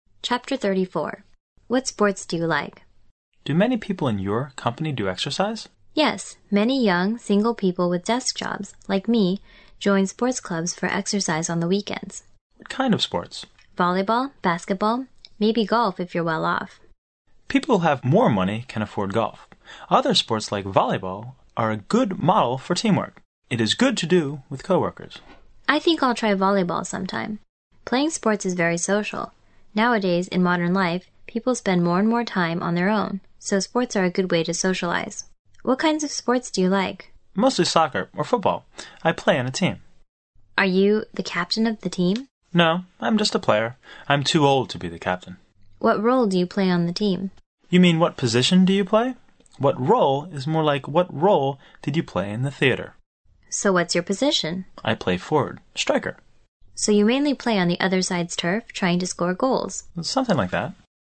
摄取生活原生态，摒弃假性交际，原汁原味的语言素材，习得口语的最佳语境。